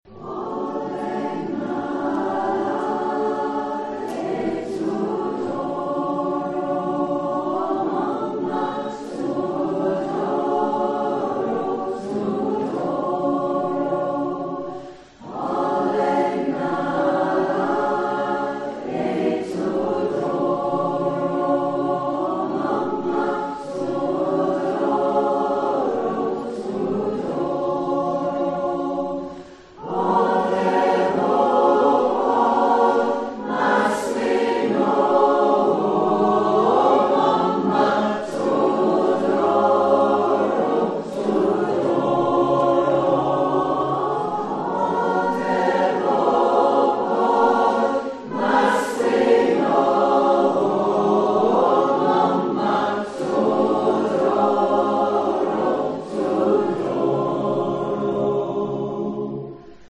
Here’s part of a song in a mystery language.
The song sounds like a Christian hymn.
I made the recording at the Bangor Community Choir rehearsal last week.